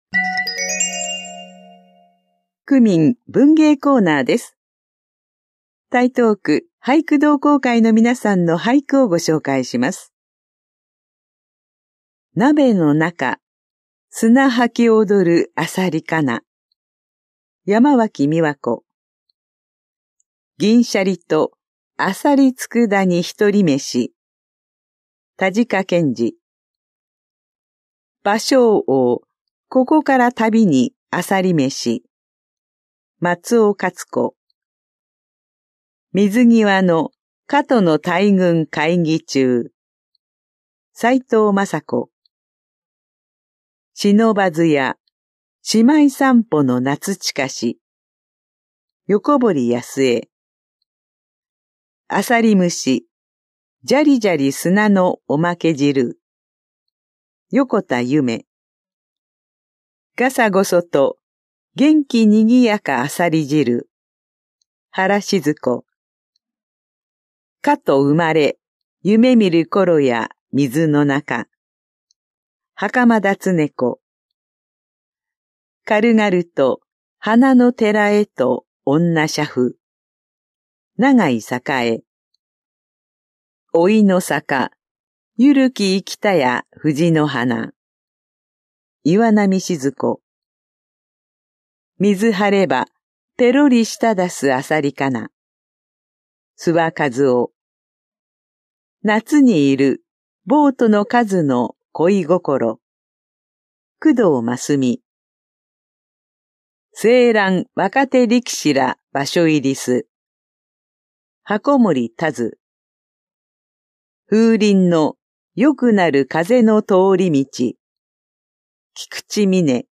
広報「たいとう」令和7年5月5日号の音声読み上げデータです。